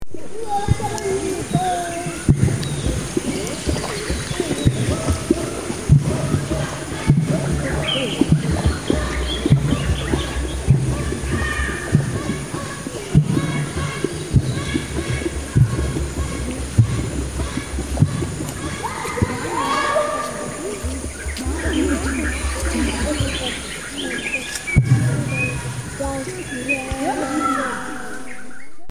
Forest sounds with distant water drumming